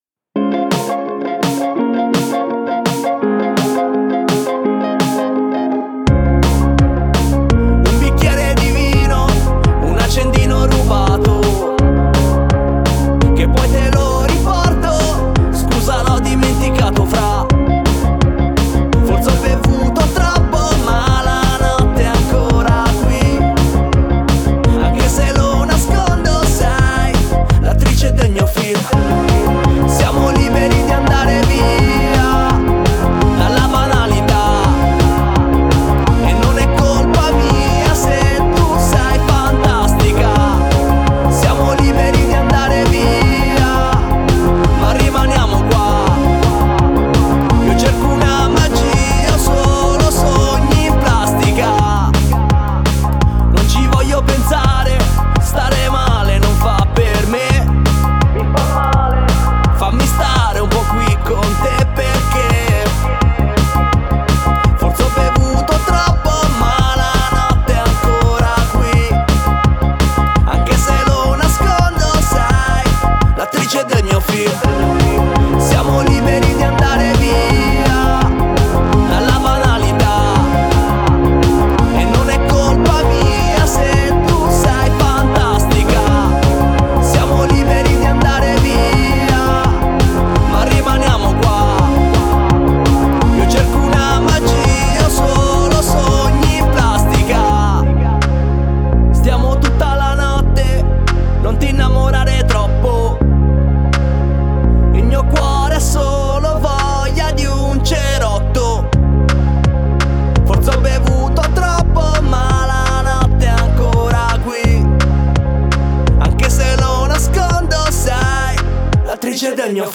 sonorità sognanti e pop